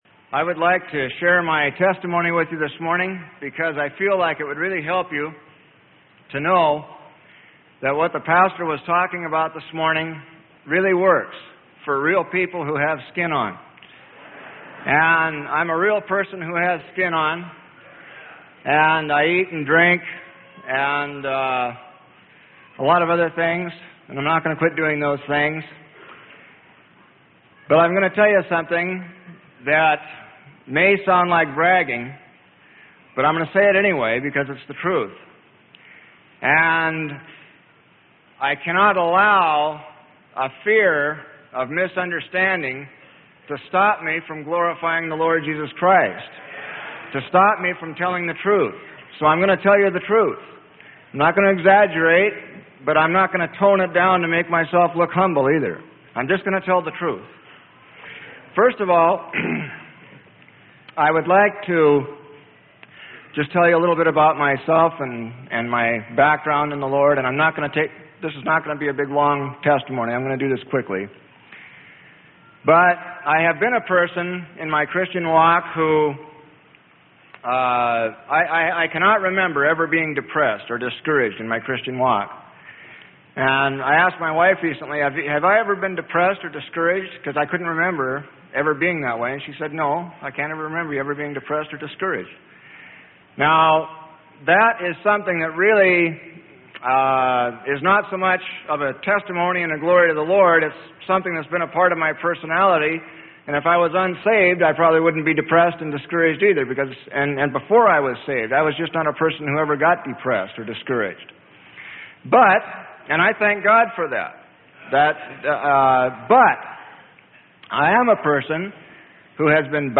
Sermon: Stop Worrying and Start Praying in Faith (Edited Full Service) - Freely Given Online Library
Stop Worrying and Start Praying in Faith (Edited Full Service) - Freely Given MP3 Library